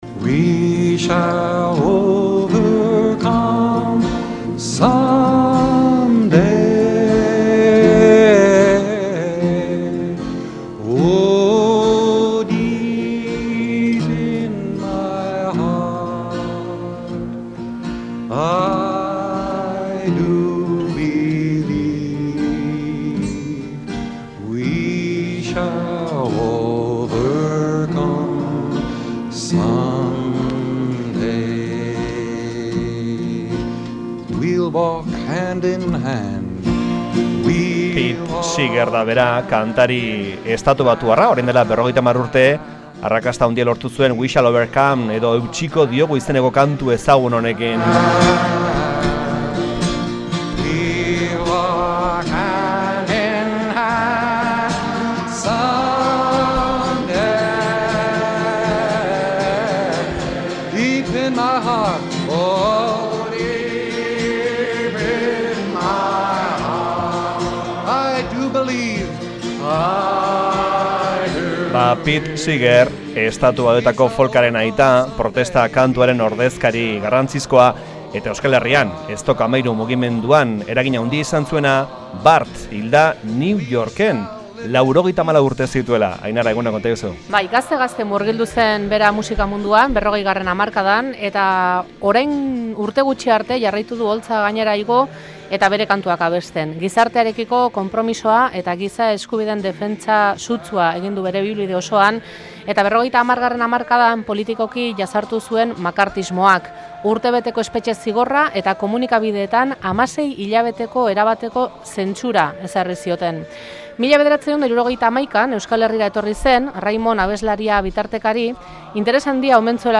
Pete Seeger 1971n Euskal Herrian egon zeneko elkarrizketa
Orduko grabazioa jaso dugu eta Benito Lertxundirekin ere hitz egin dugu.